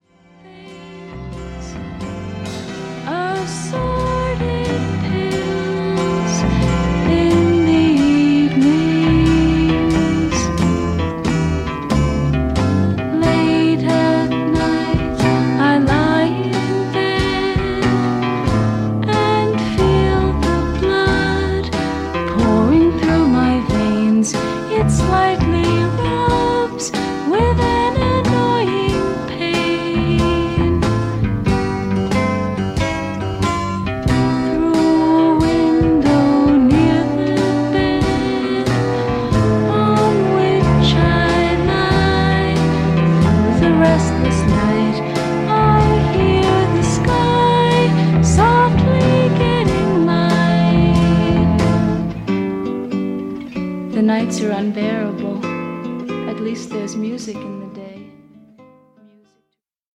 サイケデリアとアシッドフォーク感覚も併存した
どちらも見事に儚さや素朴さに溢れた美しい曲です。